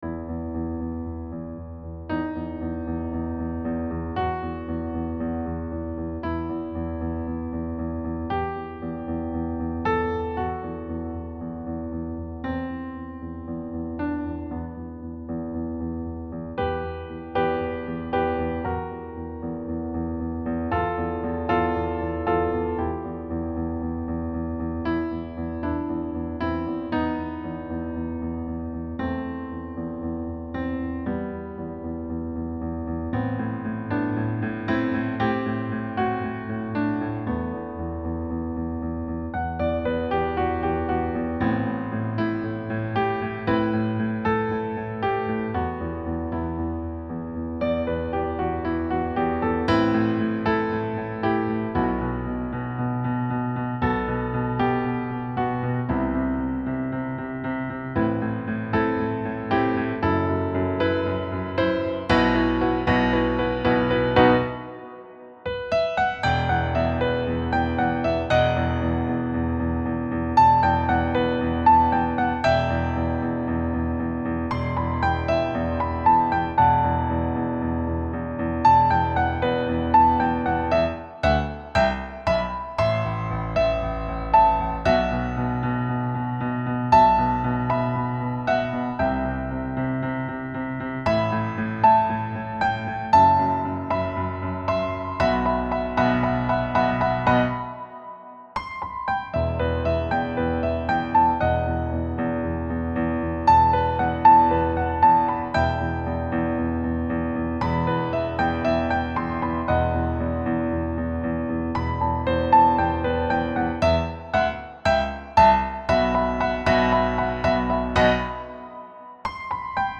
an electrifying rock-style piano solo
Key: E natural minor
Time signature: 4/4 (BPM ≈ 120)
• Driving rock rhythms and repeated left-hand bass patterns
• Syncopation and pulse in a rock groove
• Mysterious jazz harmony coloring the melody